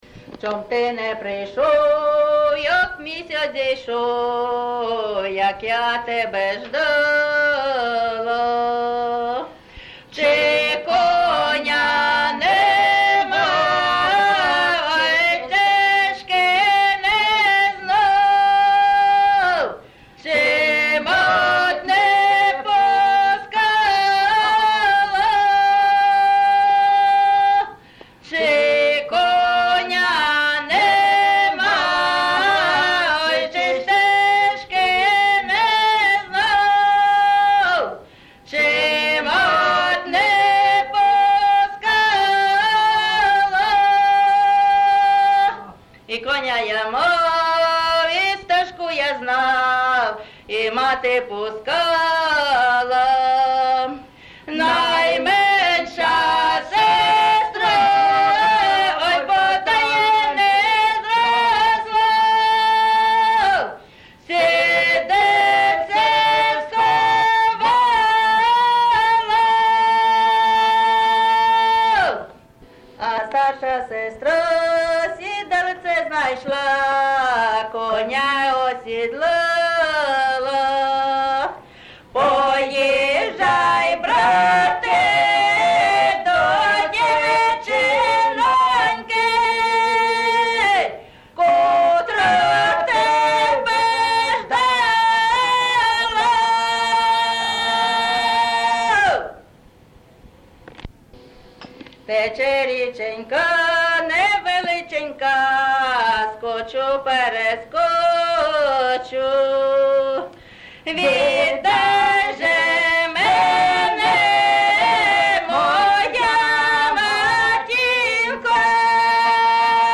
ЖанрПісні з особистого та родинного життя
Місце записус. Яблунівка, Костянтинівський (Краматорський) район, Донецька обл., Україна, Слобожанщина